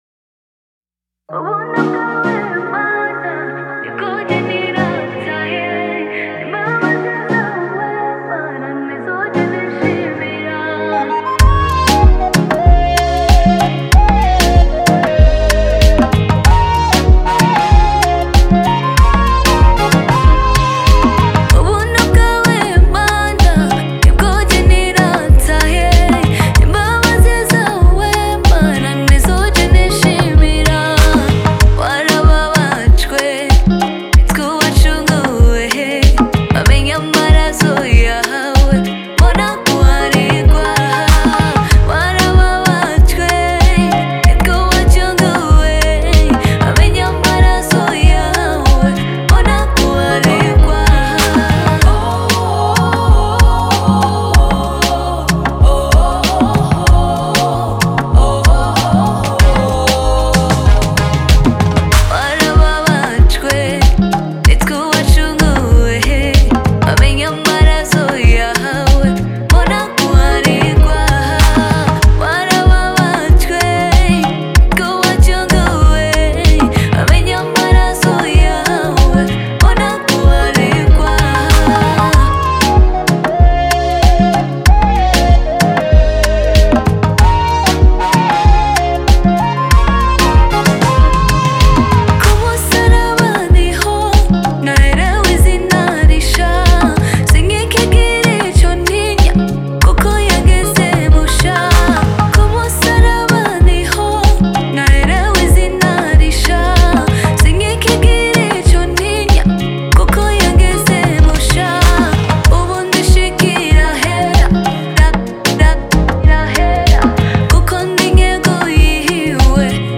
Umuririmvyikazi aririmba indirimbo zoguhimba Imana